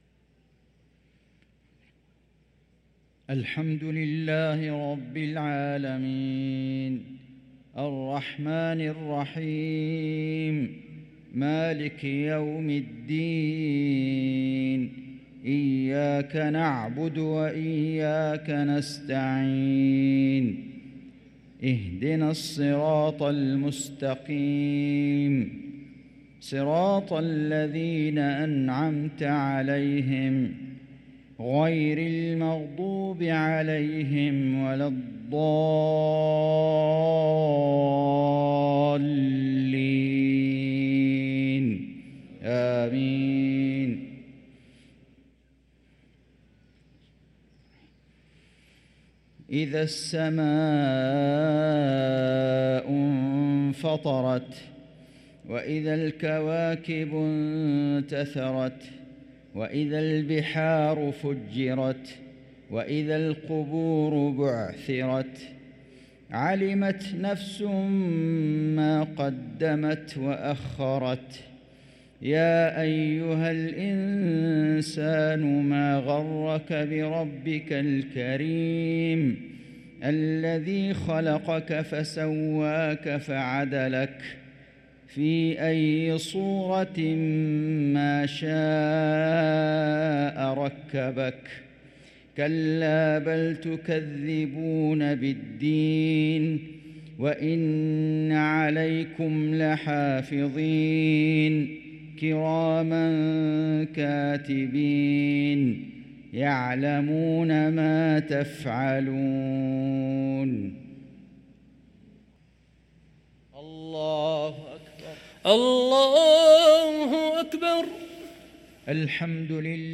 صلاة المغرب للقارئ فيصل غزاوي 25 ربيع الأول 1445 هـ